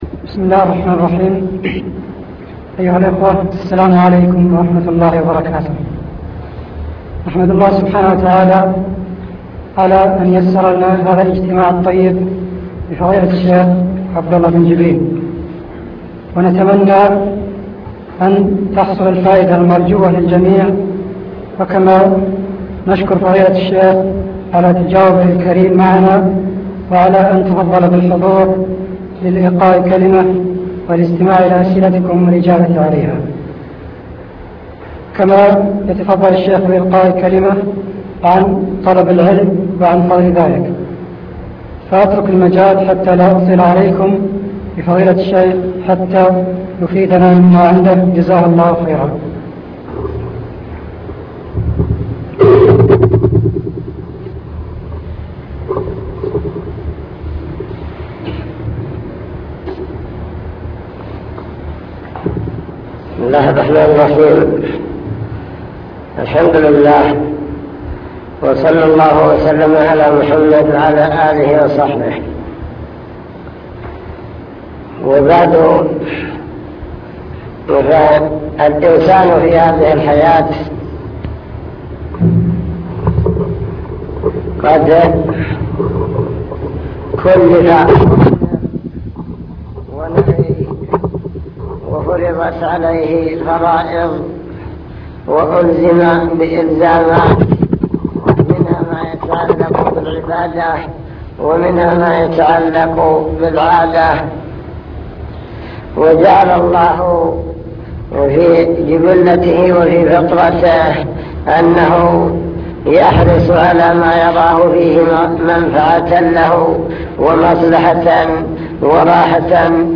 المكتبة الصوتية  تسجيلات - محاضرات ودروس  محاضرات عن طلب العلم وفضل العلماء أهمية التفقه في الدين وكيفية تحقيق معنى العبودية